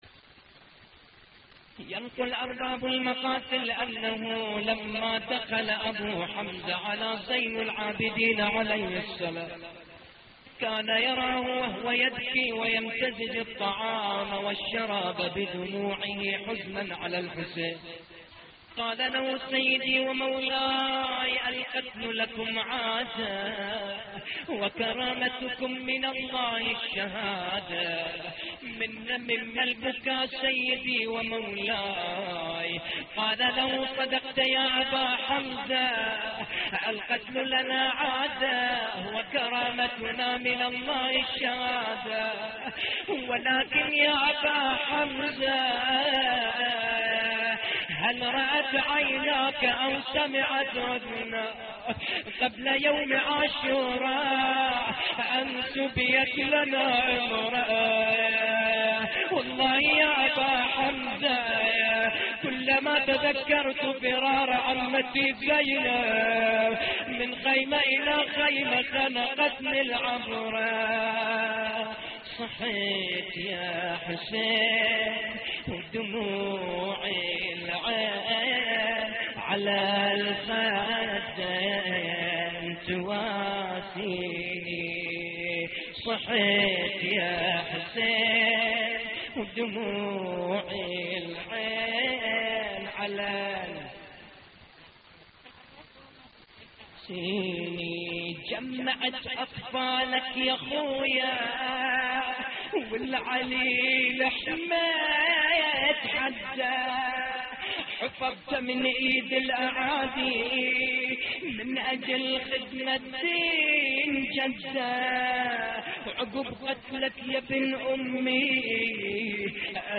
الإمام زين العابدين يبكي ويمزج الطعام بدموعه (نعي
اللطميات الحسينية